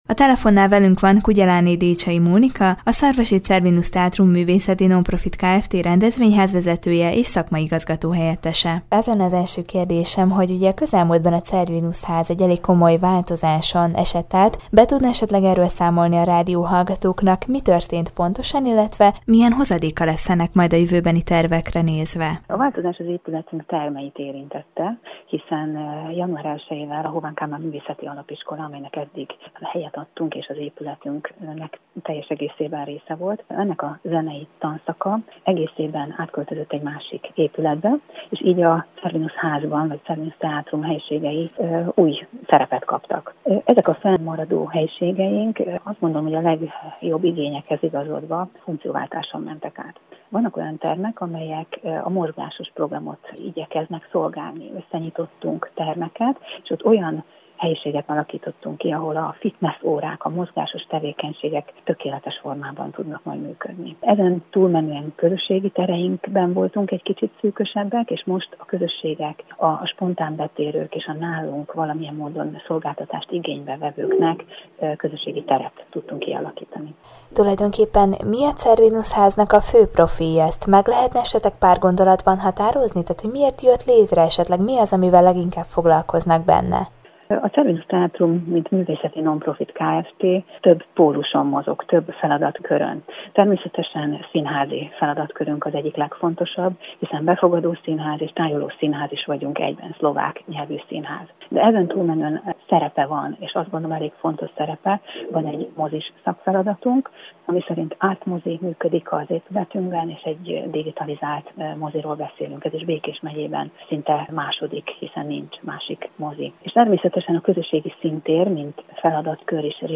Új termeket alakítottak ki a szarvasi Cervinus Házban, amelyeket be is vonnak a következő programokba. Hétvégén ugyanis megrendezésre kerül a Kultúrházak éjjel-nappal. Erről kérdezte tudósítónk